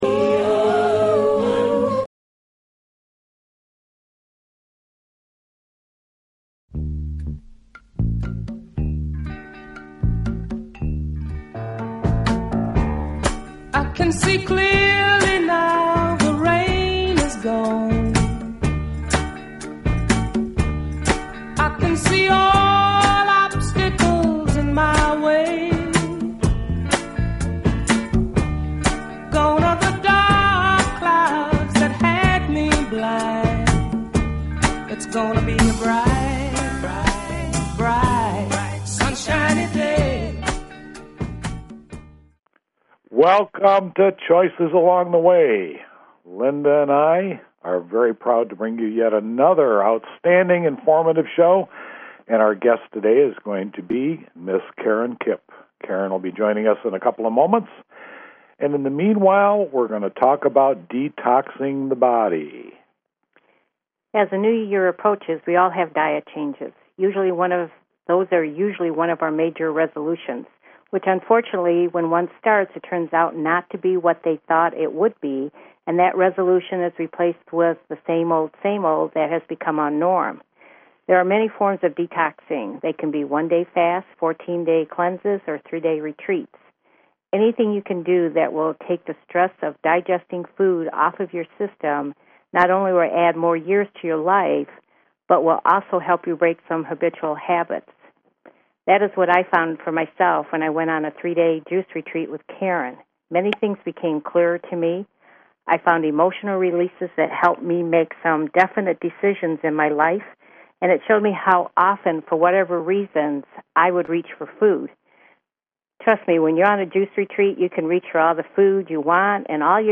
Talk Show Episode, Audio Podcast, Choices_Along_The_Way and Courtesy of BBS Radio on , show guests , about , categorized as